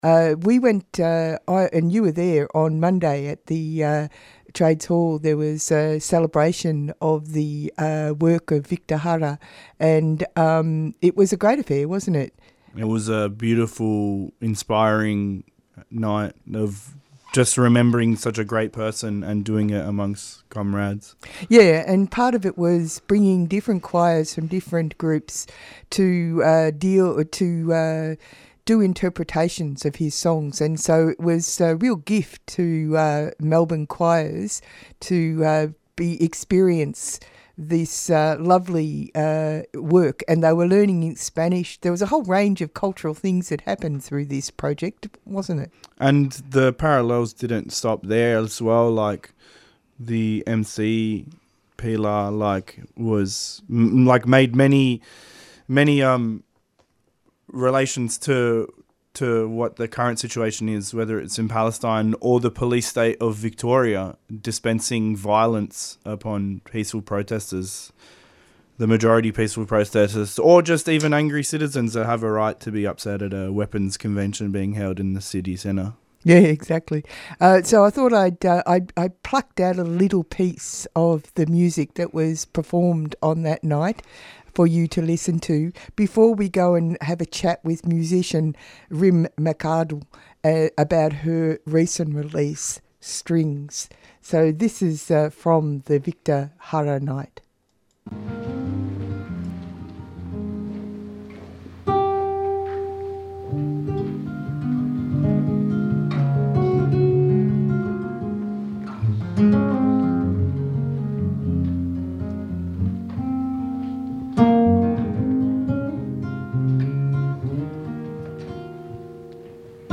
here II a beautiful piece of music which was part of the September 16 night honouring Victor Jara's music held at the Victorian Trades Hall.